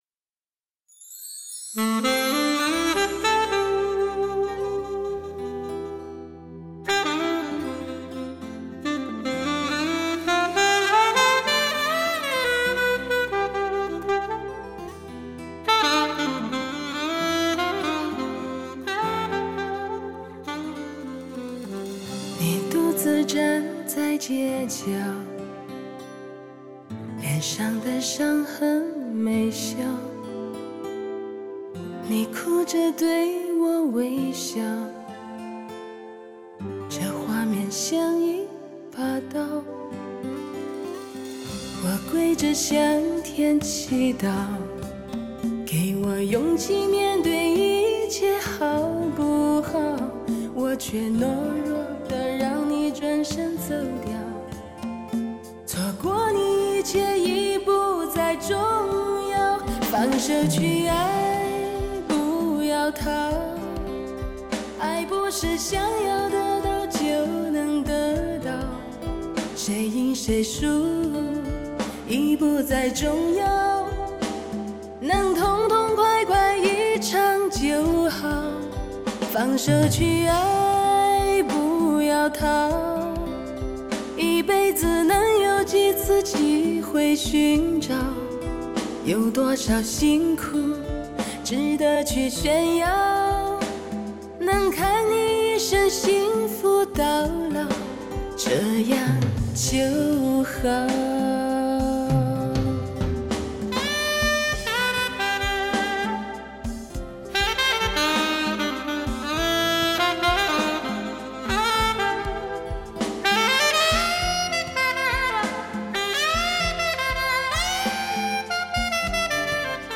完美环绕+最新科技DTS-ES6.1，还原最真实的HI-FI声场效果营造最逼真的现场氛围。